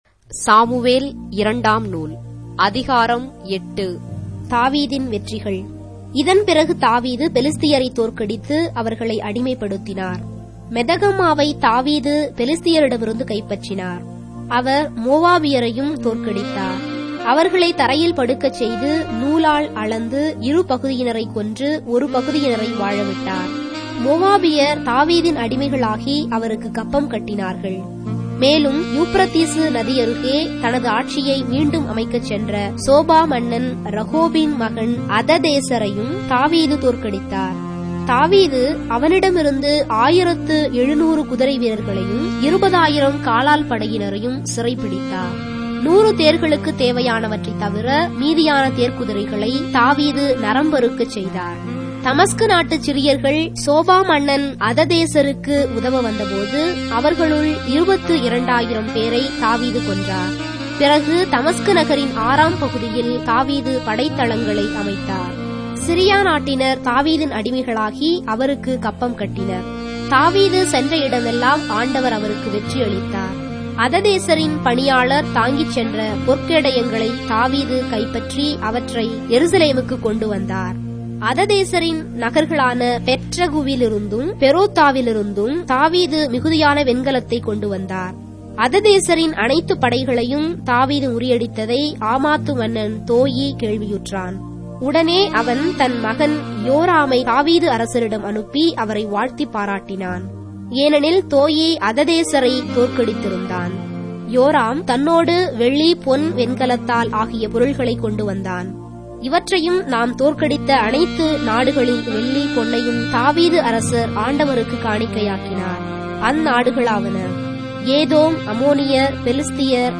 Tamil Audio Bible - 2-Samuel 1 in Ecta bible version